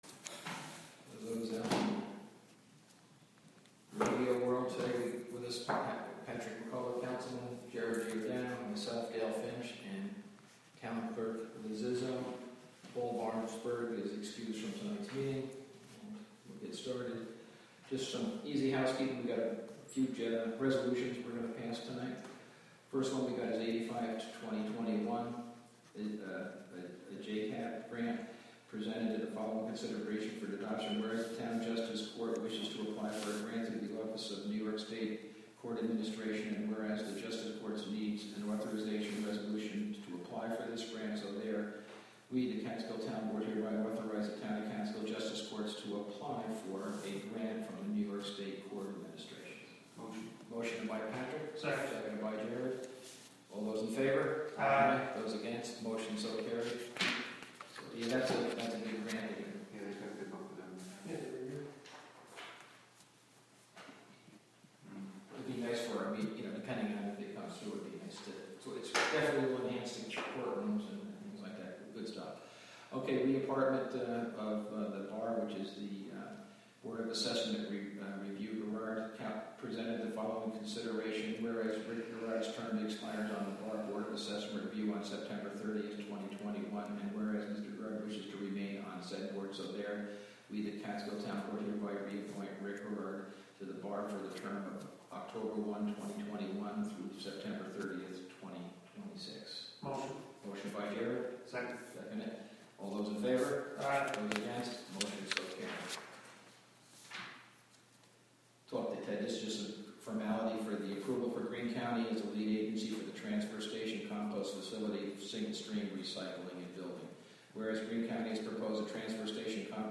Live from the Town of Catskill: Town Board Meeting September 15, 2021 (Audio)